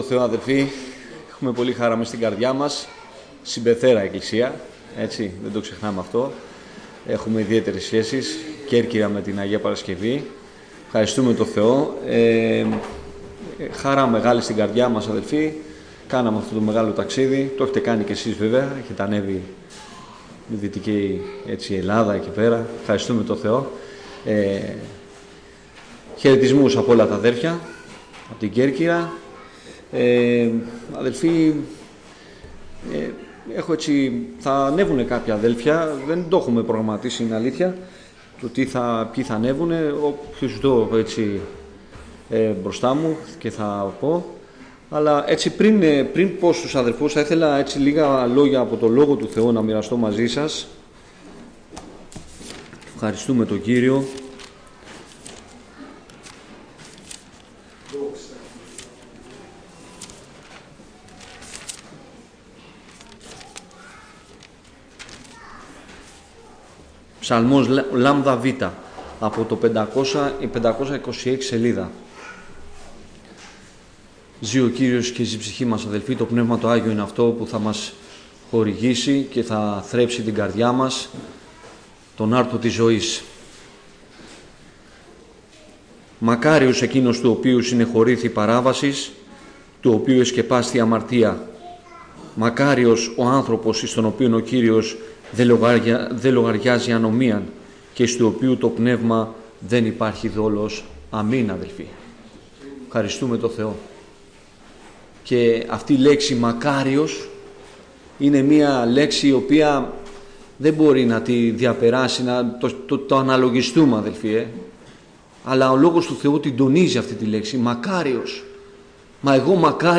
Κήρυγμα Παρασκευής, στις 03/04/2026, ομιλιτές οι καλεσμένοι απο διάφορες εκκλησίες.